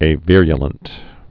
(ā-vîryə-lənt, ā-vîrə-)